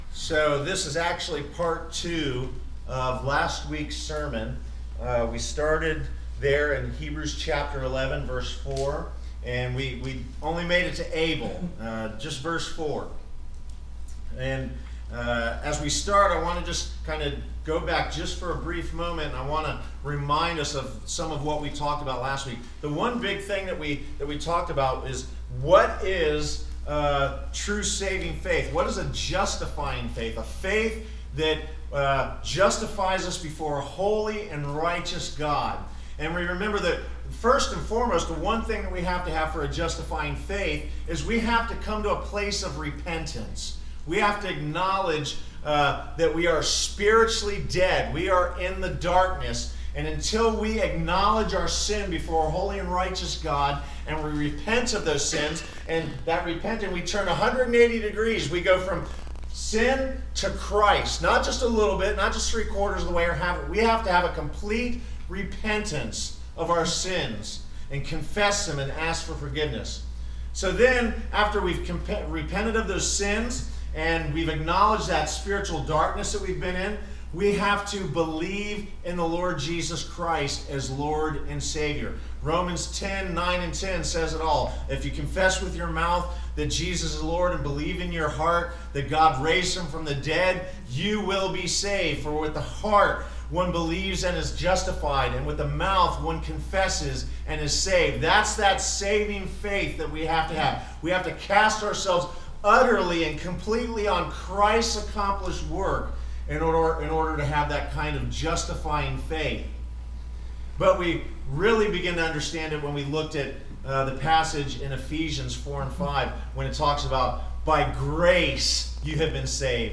Passage: Hebrews 11:5-7 Service Type: Sunday Morning